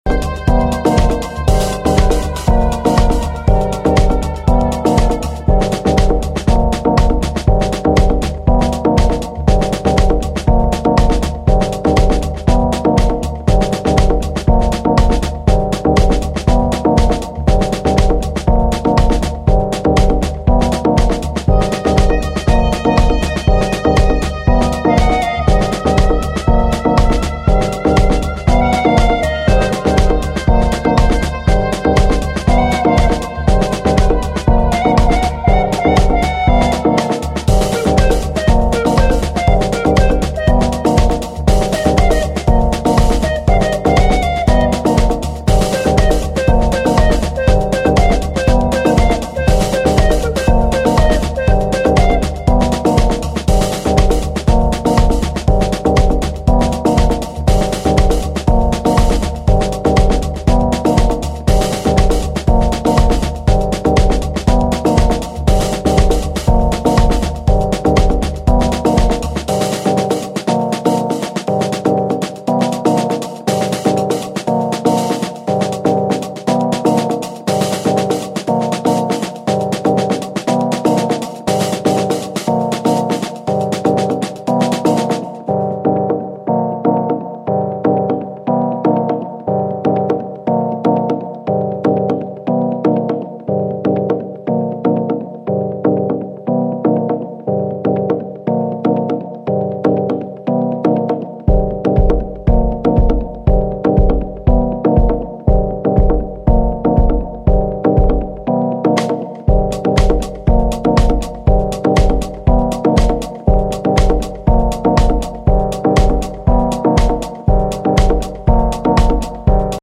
deep house jewel
wicked hi hats that shread through this massive track
melodic keys and pads